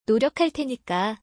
ノリョカ テニカ